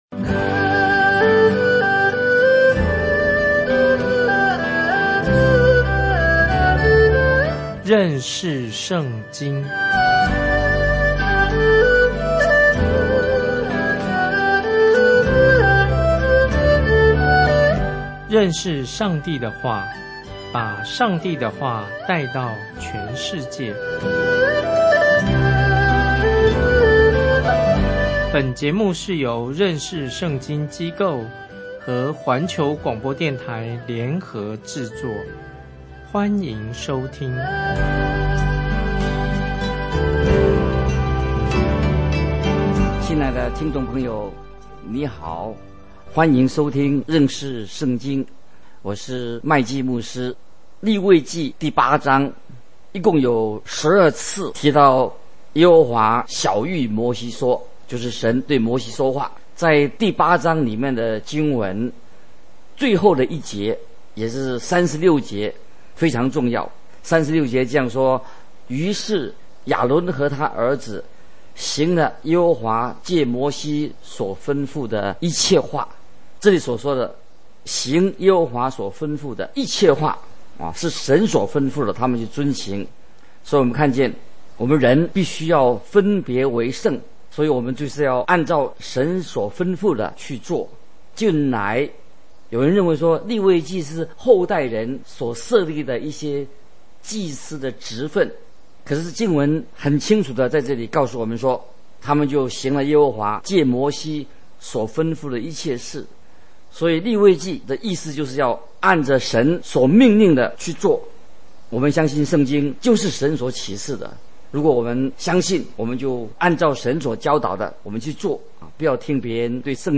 這是個每天30分鐘的廣播節目，旨在帶領聽眾有系統地查考整本聖經。